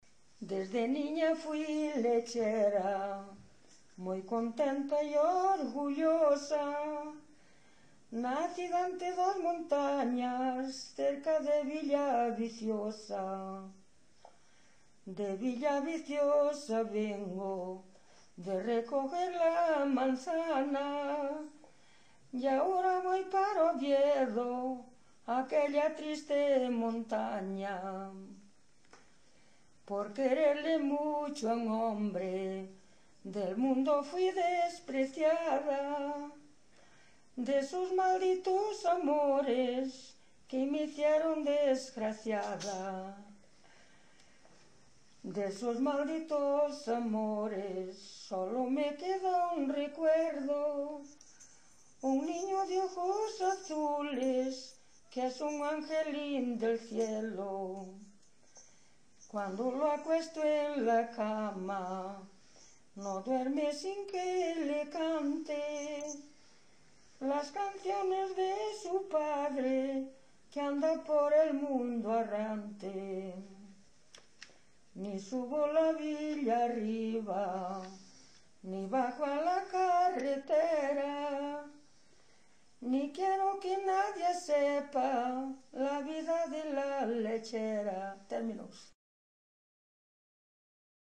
Tipo de rexistro: Musical
Áreas de coñecemento: LITERATURA E DITOS POPULARES > Coplas
Lugar de compilación: Vila de Cruces - Sabrexo (Santa María) - Sabrexo
Soporte orixinal: Casete
Datos musicais Refrán
Instrumentación: Voz
Instrumentos: Voz feminina